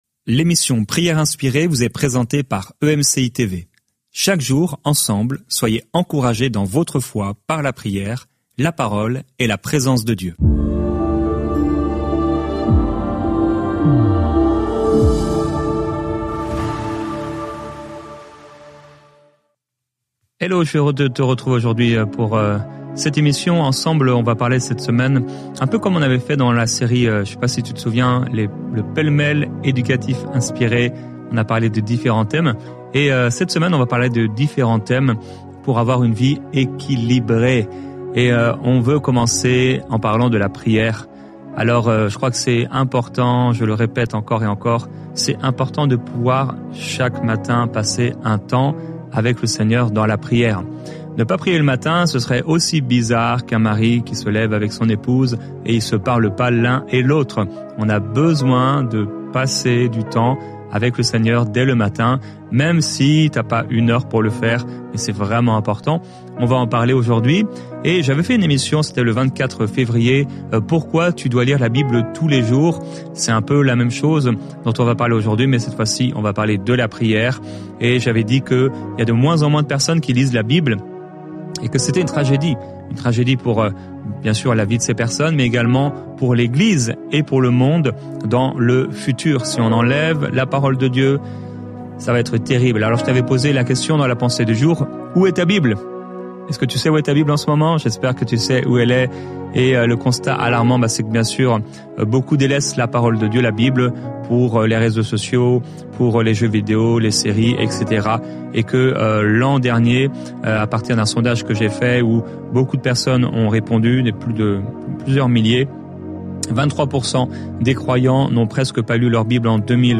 ⬇ TÉLÉCHARGER L'APPLICATION L'émission quotidienne « Prières inspirées » a pour but de rapprocher les croyants du cœur de Dieu, par la prière, des encouragements et des chants.
Au programme, une pensée du jour, un temps de louange, l'encouragement du jour et un temps de prière et de déclaration prophétique.